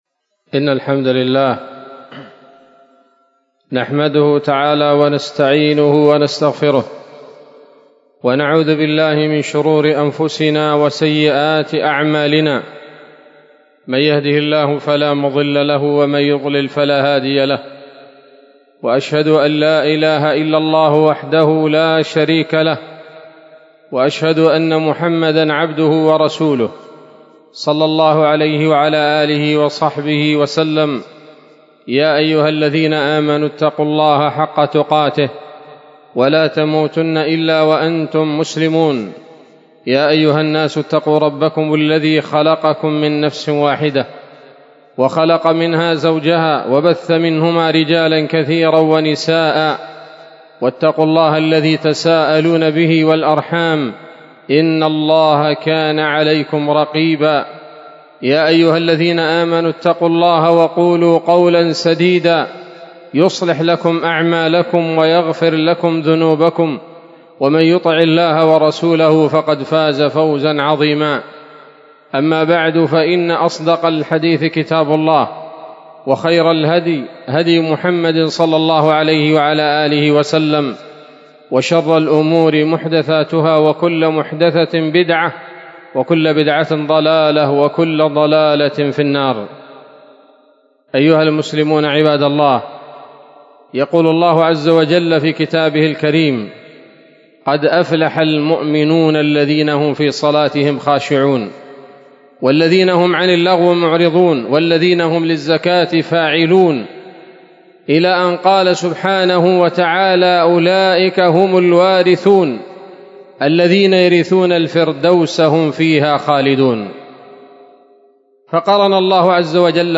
خطبة جمعة بعنوان: (( الخاشعون في صلاتهم )) 24 ربيع الأول 1446 هـ، دار الحديث السلفية بصلاح الدين